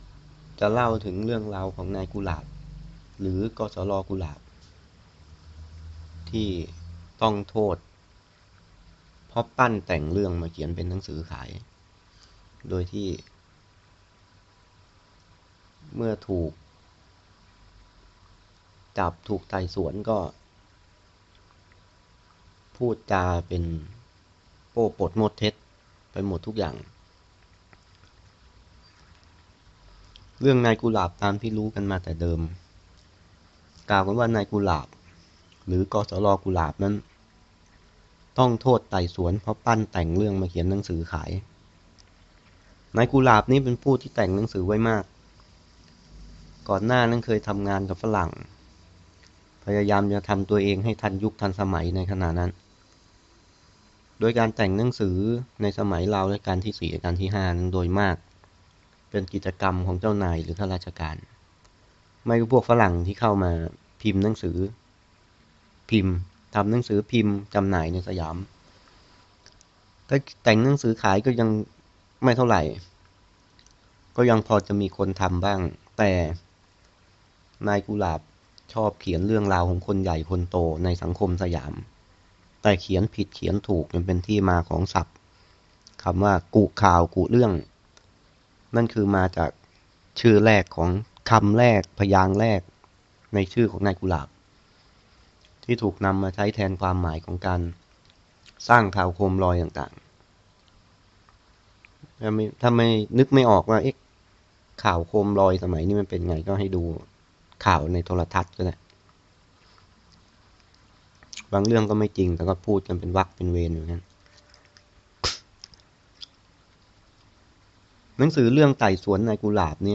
หนังสือเสียงเรื่องหนังสือไต่สวนนายกุหลาบ.mp3